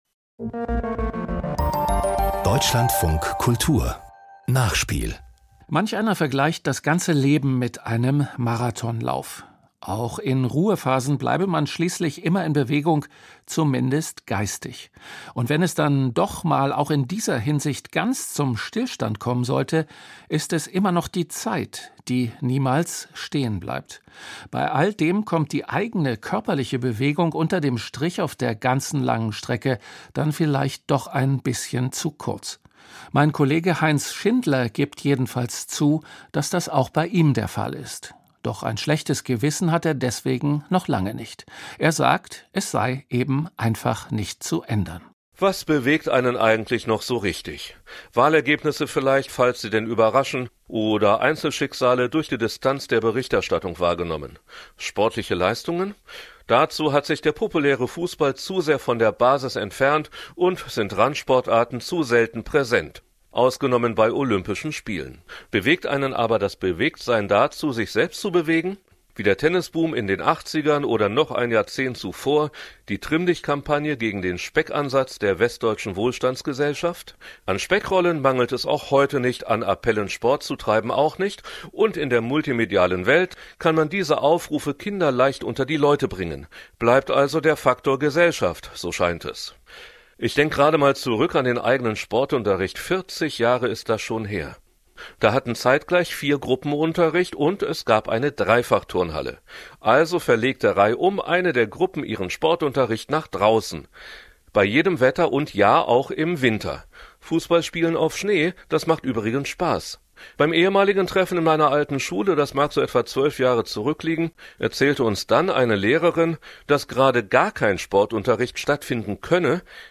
Kommentar: Warum es an Bewegung mangelt